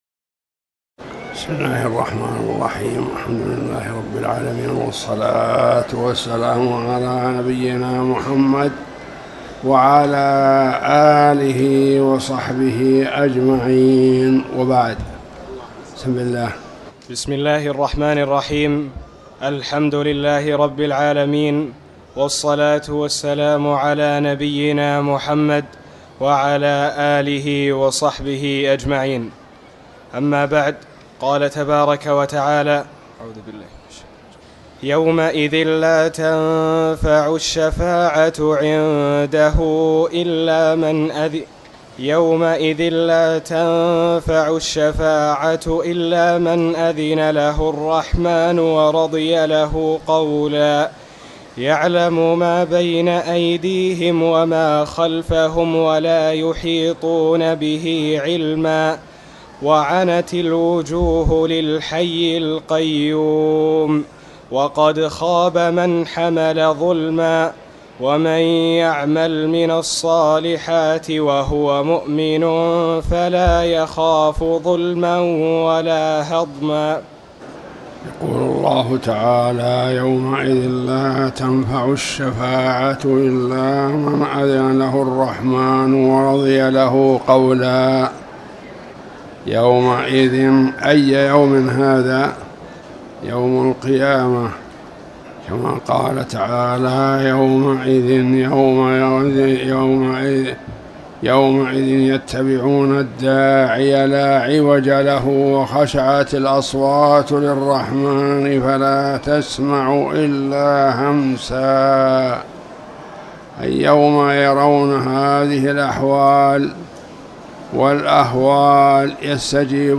المكان: المسجد الحرام